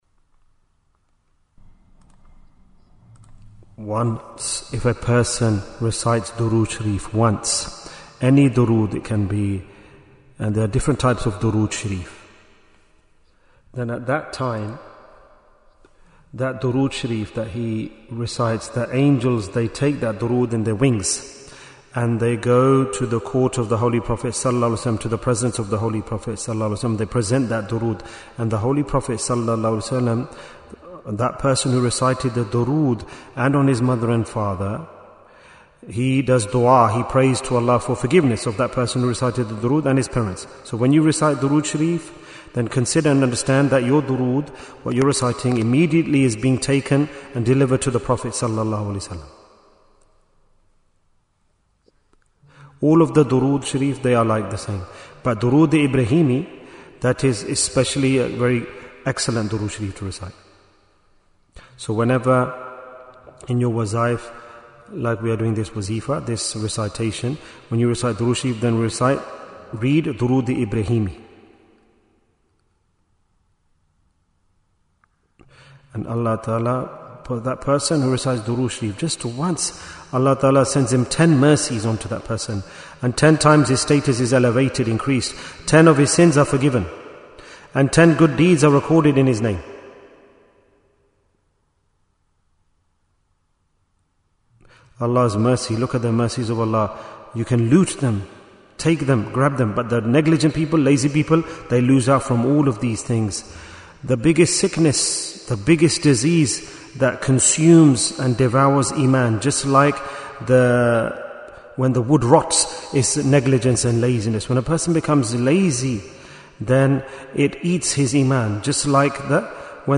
Bayan, 9 minutes